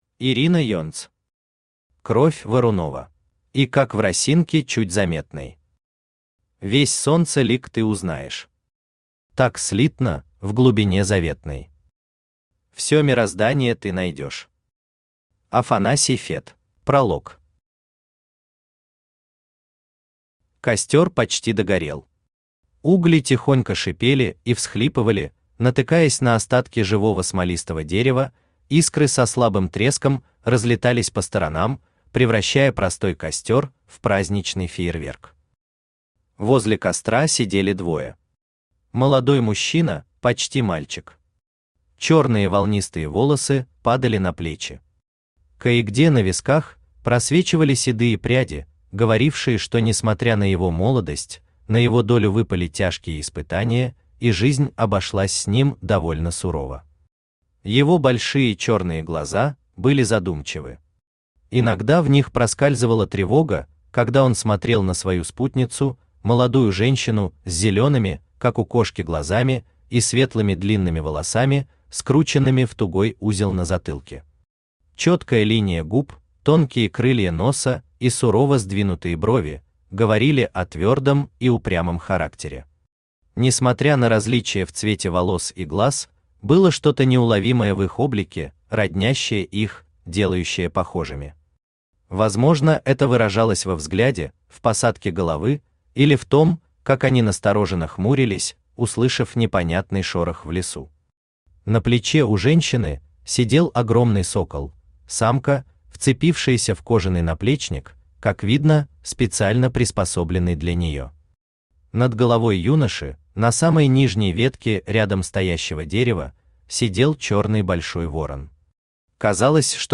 Аудиокнига Кровь Варунова | Библиотека аудиокниг
Aудиокнига Кровь Варунова Автор Ирина Юльевна Енц Читает аудиокнигу Авточтец ЛитРес.